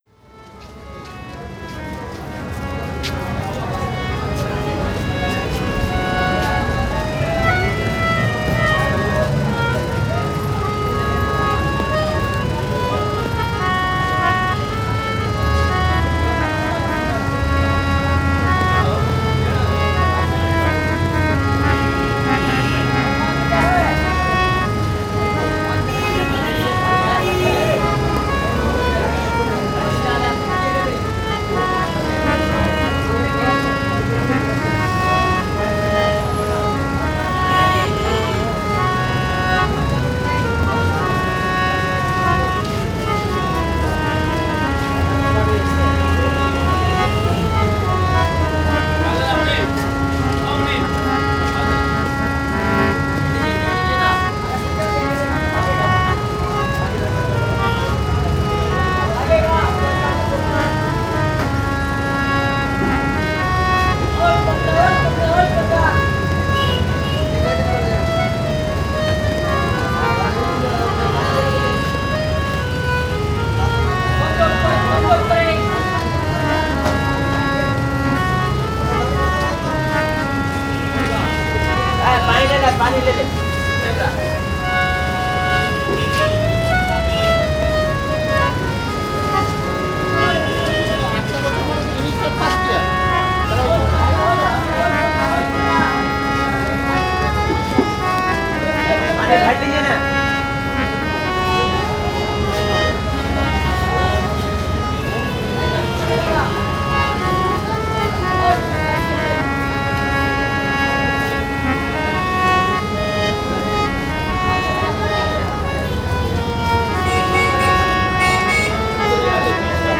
Chowringhee路上的乞丐在演奏簧风琴，录于1998年6月
A beggar on Chowringhee Road playing the harmonium